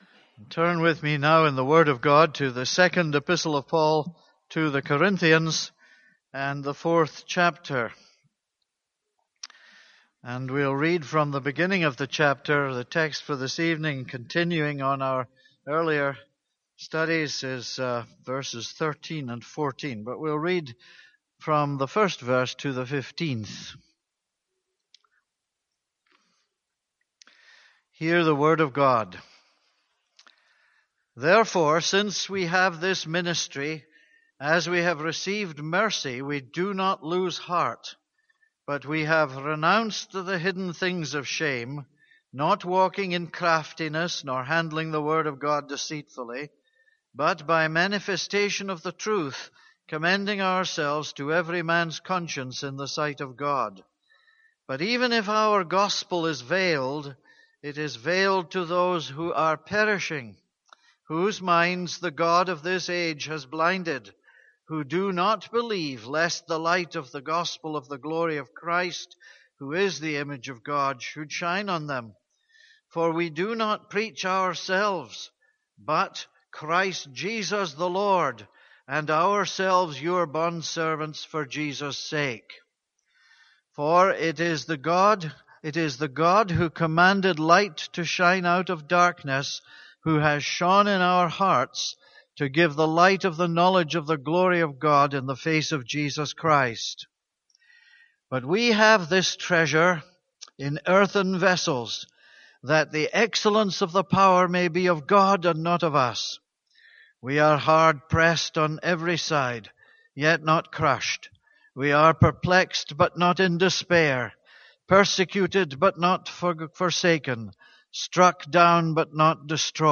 This is a sermon on 2 Corinthians 4:1-14.